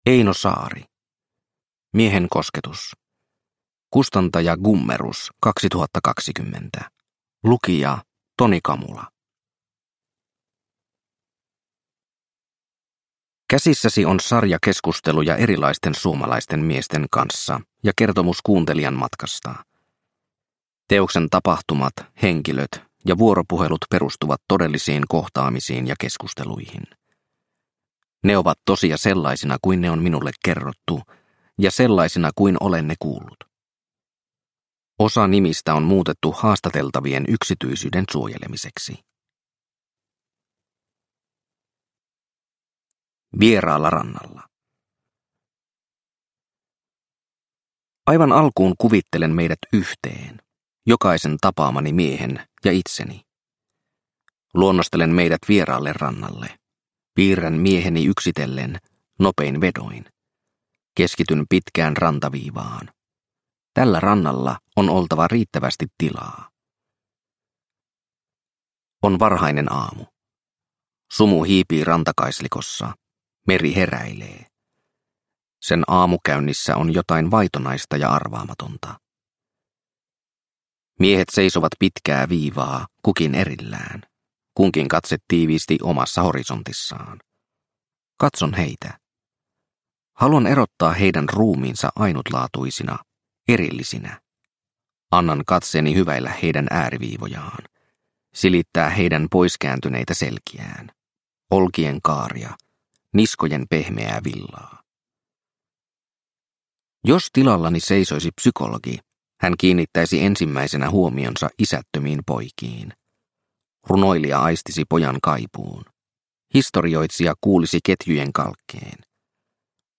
Miehen kosketus – Ljudbok – Laddas ner